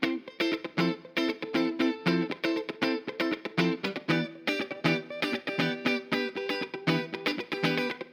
28 Guitar PT3.wav